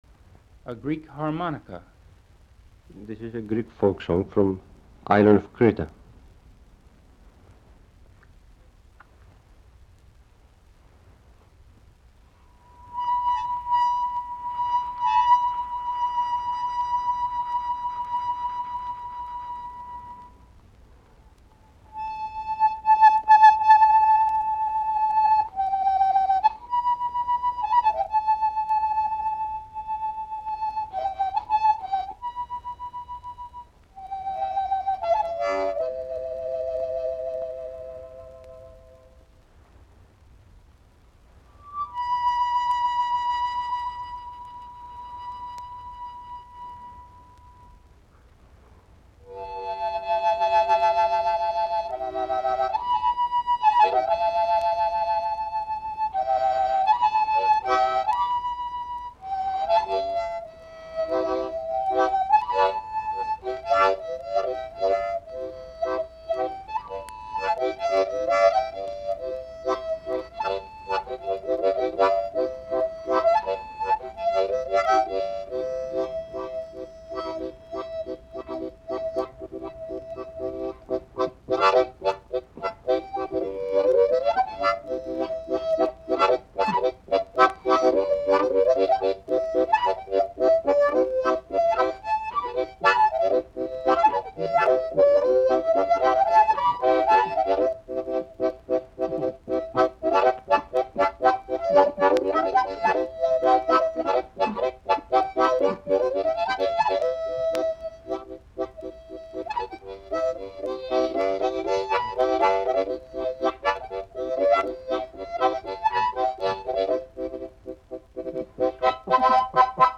13. A Greek harmonica